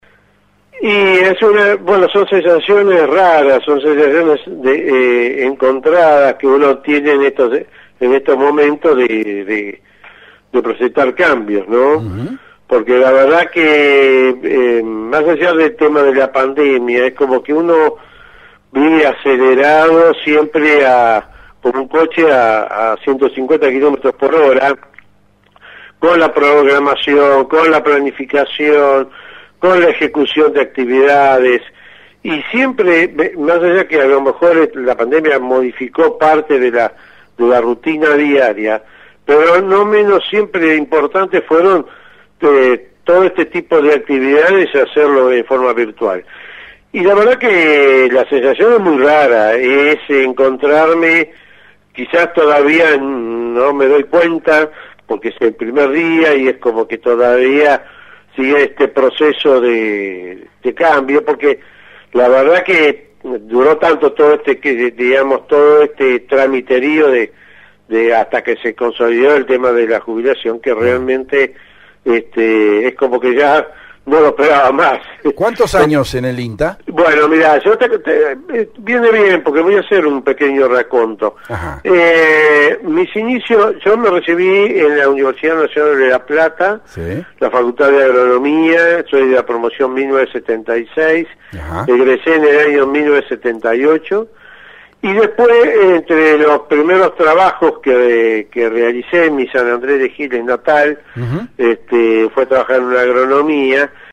EMOTIVA ENTREVISTA.